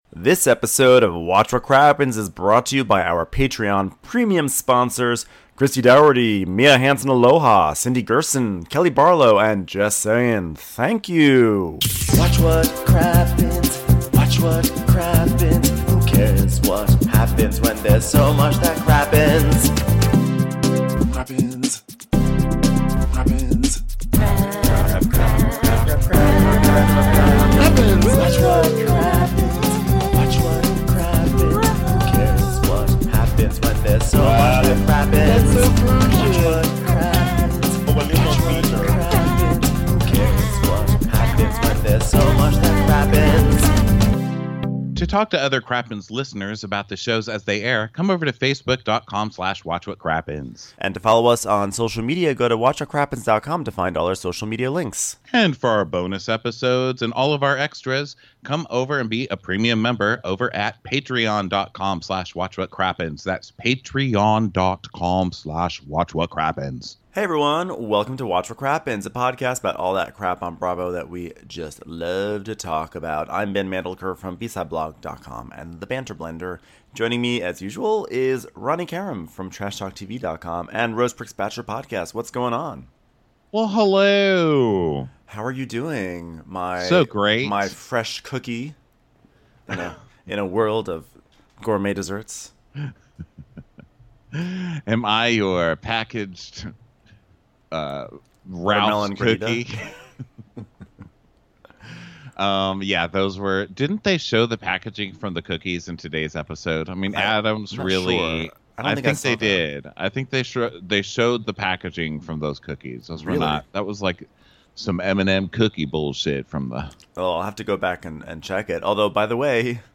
Nevertheless, we've got the latest episode of "Below Deck Mediterranean" covered, now with 110% more inaccurate accents. Stick around afterwards for Crappens Mailbag!